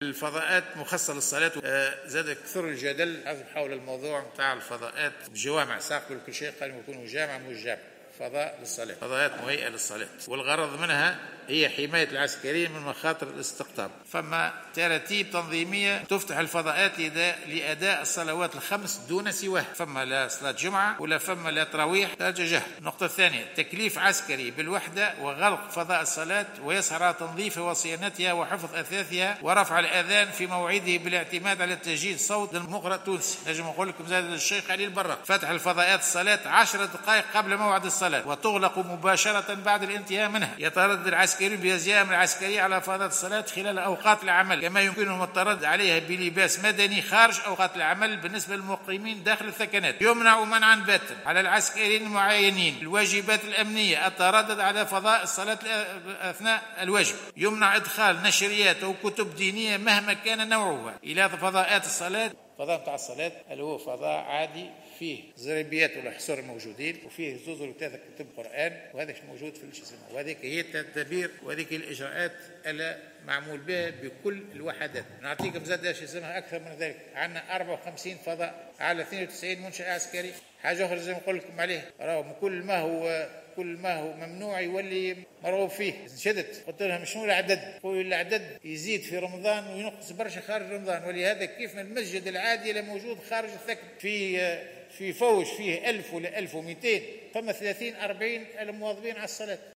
رد وزير الدفاع الوطني عبد الكريم الزبيدي اليوم الأربعاء، خلال جلسة عامة بمجلس نواب الشعب خصصت للمصادقة على ميزانية وزارته، على الجدل القائم منذ فترة حول فضاءات الصلاة داخل الثكنات العسكرية.